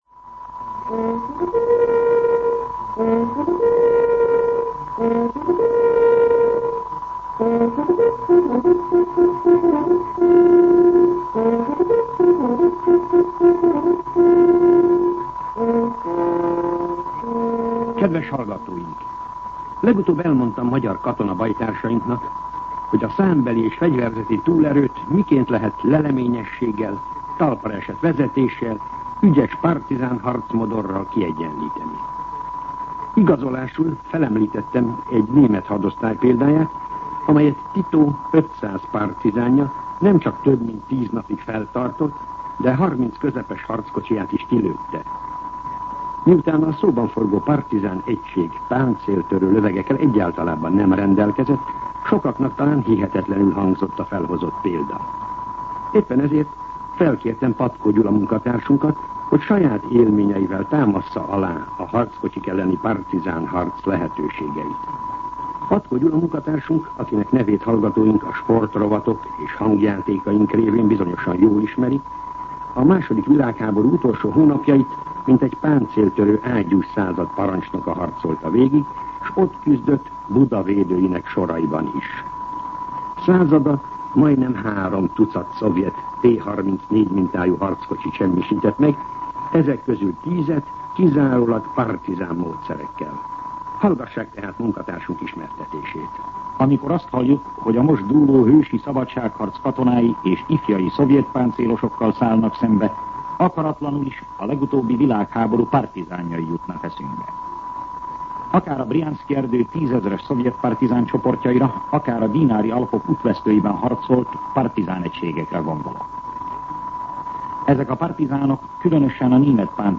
Katonapolitikai kommentár